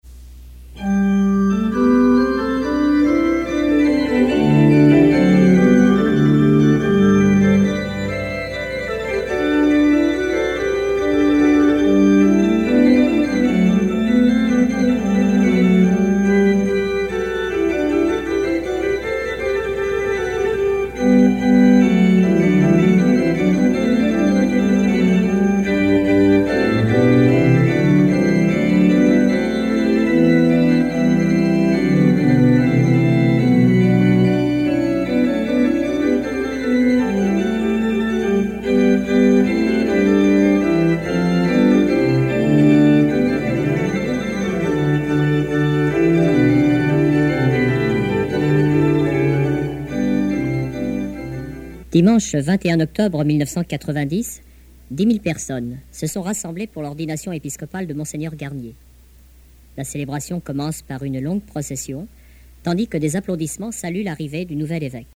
cérémonie religieuse
prière, cantique
Pièce musicale inédite